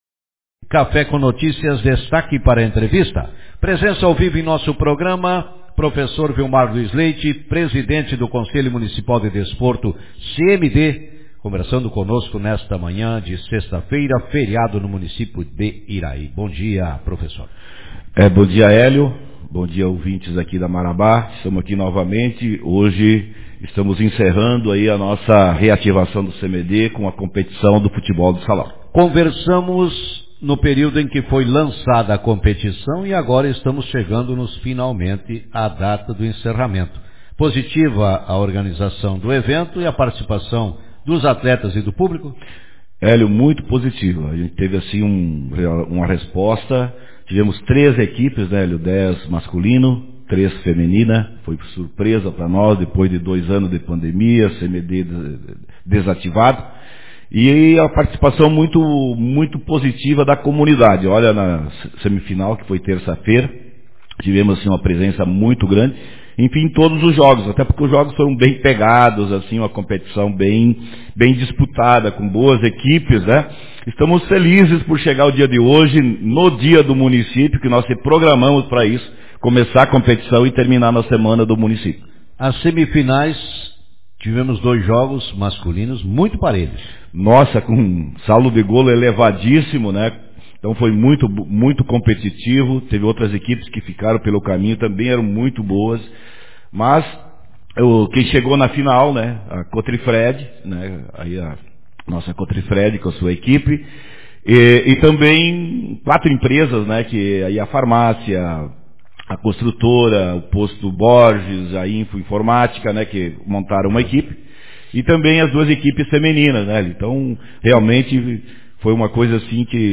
Manchete